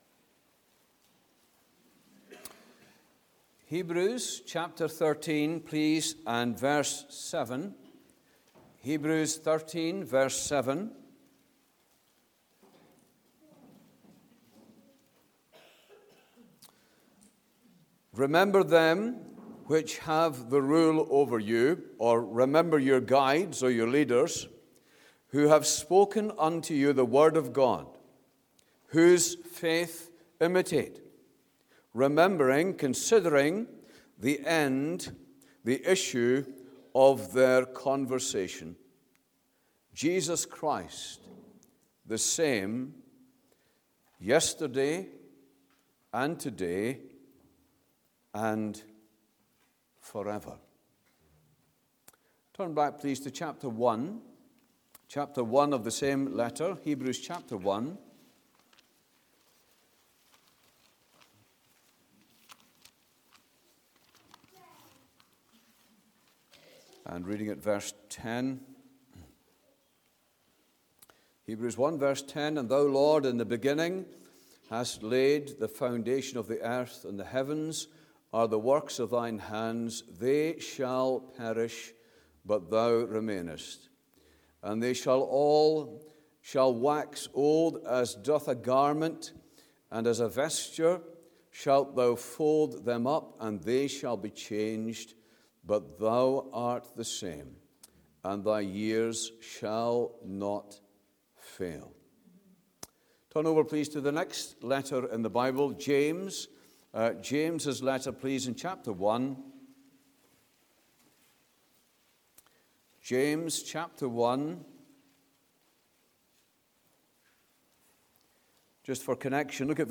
2026 Easter Conference